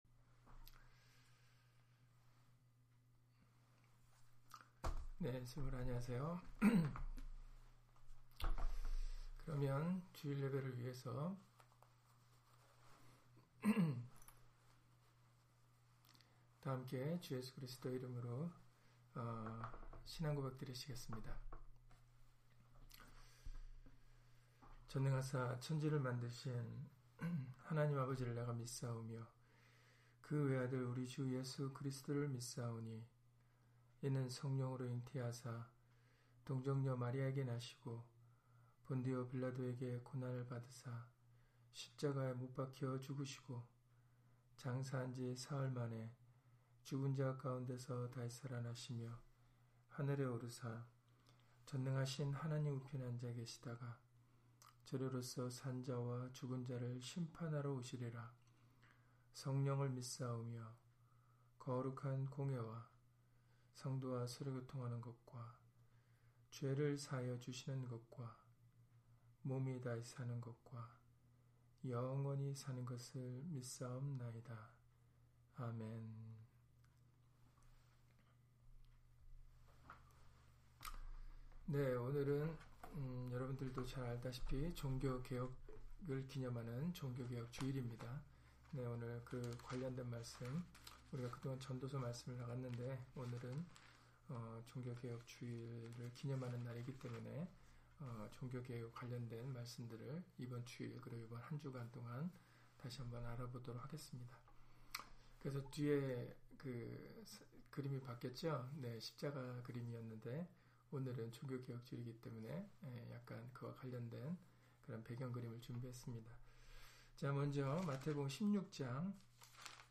마태복음 16장 13-19절 [종교개혁 주일] - 주일/수요예배 설교 - 주 예수 그리스도 이름 예배당